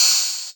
Gamer World Open Hat 9.wav